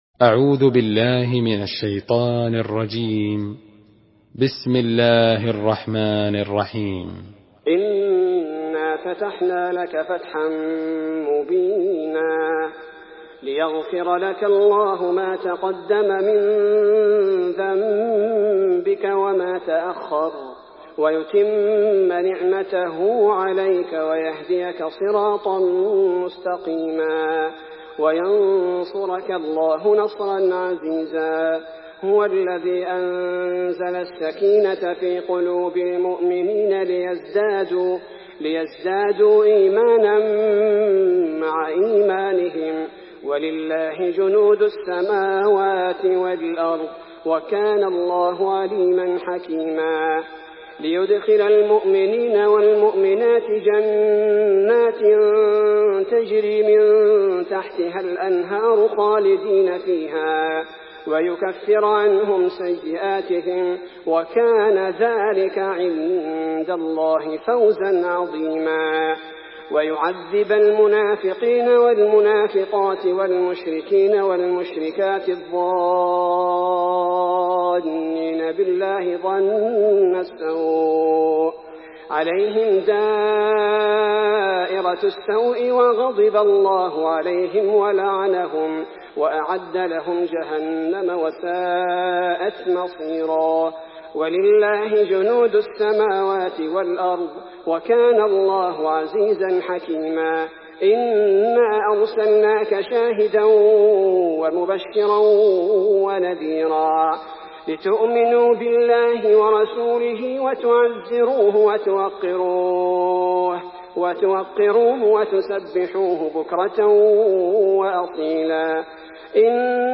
سورة الفتح MP3 بصوت عبد الباري الثبيتي برواية حفص
مرتل